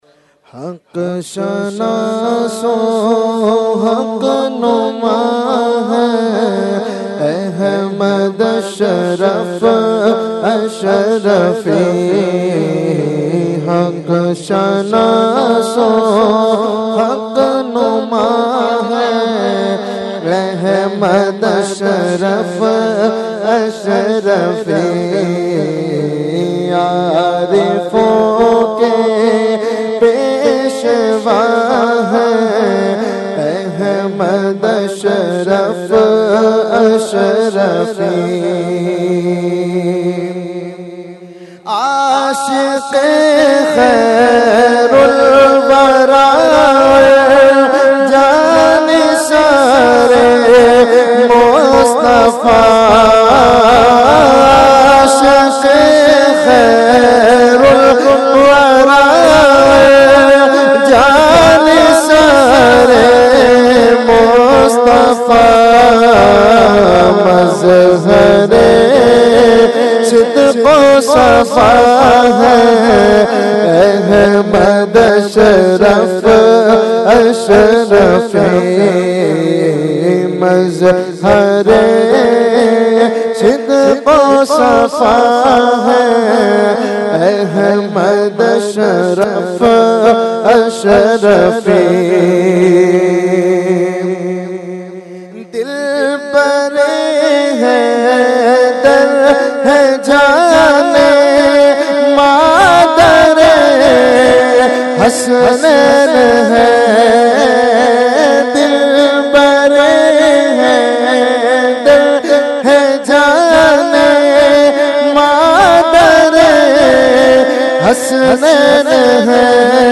Category : Manqabat | Language : UrduEvent : Urs Qutbe Rabbani 2020